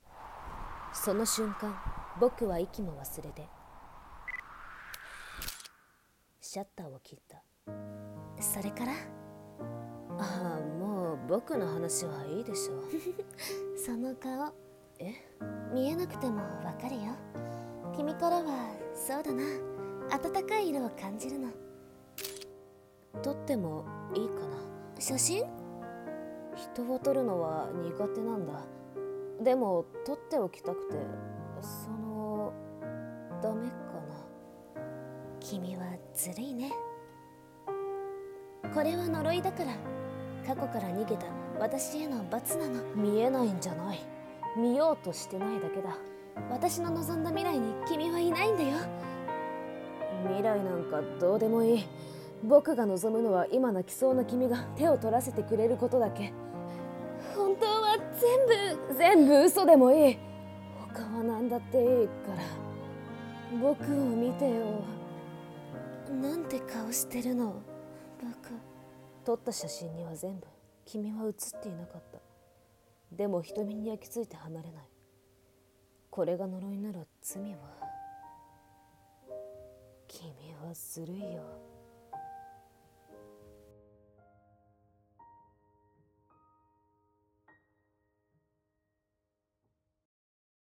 【声劇】timoria